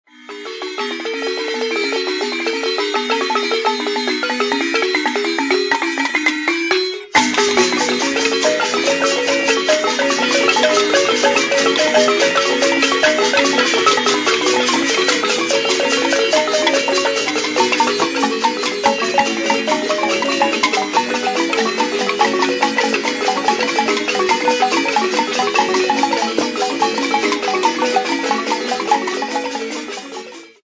Folk music--Africa
Folk songs, Chopi
Field recordings
sound recording-musical
Orchestral dance with 6 Timbila xylophones, 4 Sanzhe (Alto), 1 Debiinda (Bass), 1 Gulu (Double Bass).
96000Hz 24Bit Stereo